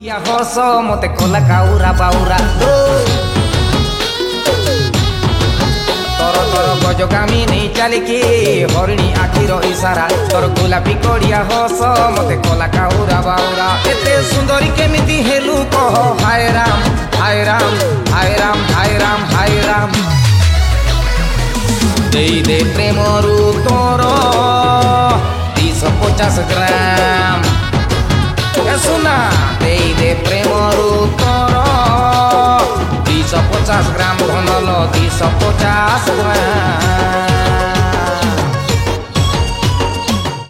Odia Album Ringtones
Romantic song